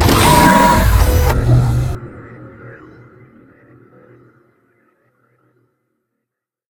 combat / enemy / droid / bigatt1.ogg